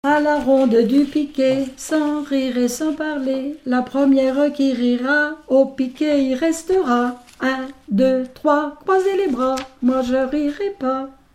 Herbiers (Les)
enfantine : lettrée d'école
gestuel : danse
Pièce musicale inédite